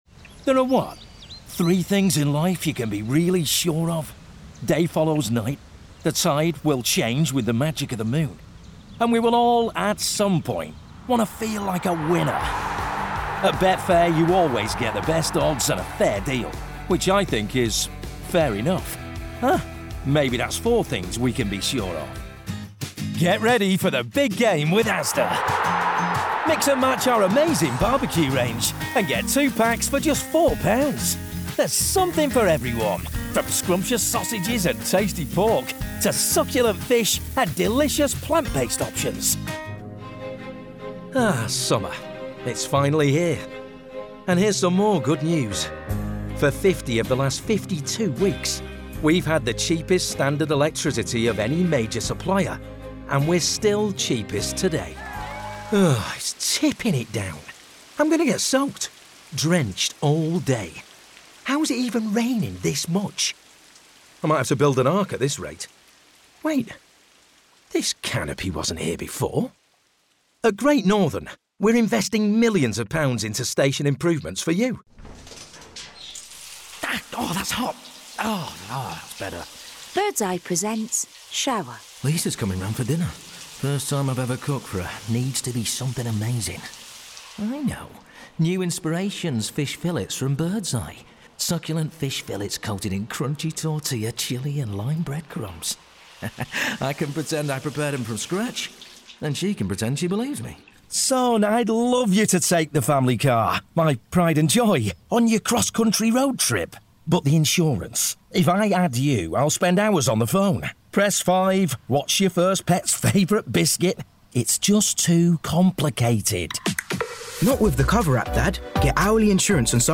40's Northern/Manchester, Assured/Energetic/Comedic